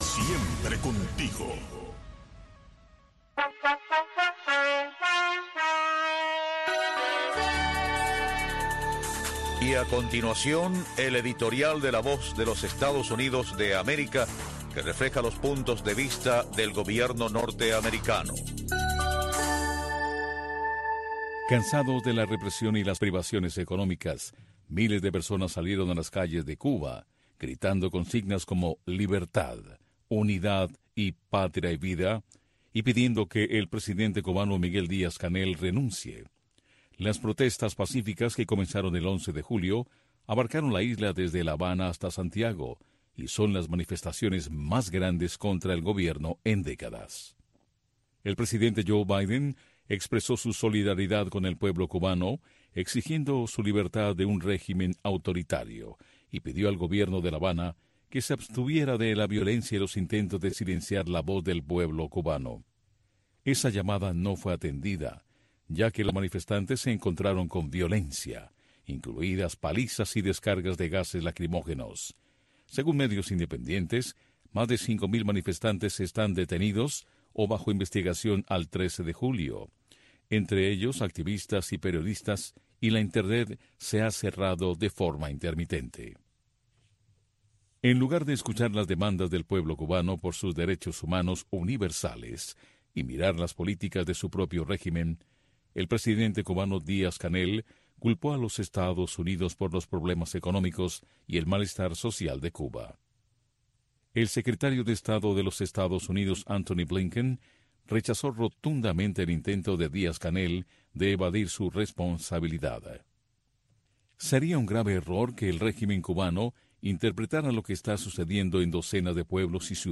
Radio Martí les ofrece una revista de entrevistas, información de la actualidad mundial vista desde el punto de vista, Entre Dos Rios.